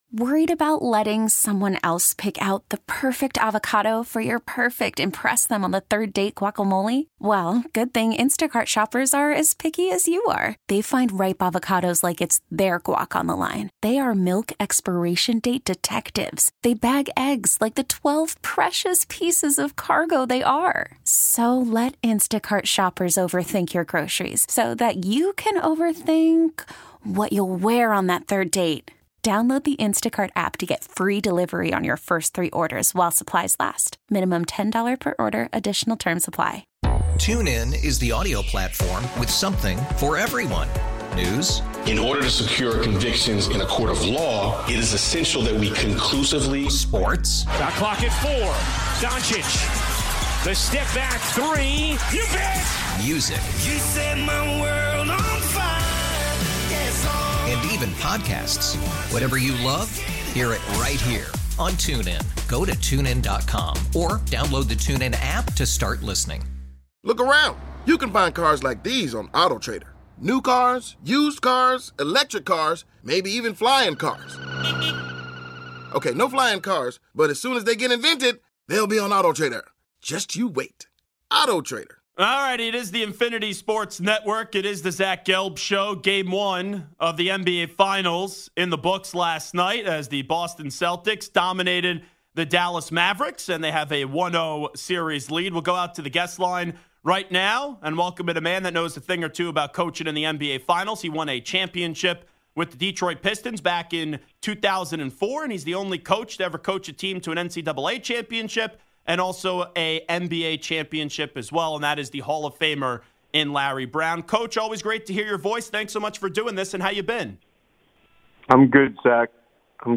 Larry Brown, Former NBA & CBB Champion Coach joins the show to discuss his past teams, Allen Iverson, and Dan Hurley’s decision on whether to stay at UCONN or go to the Lakers.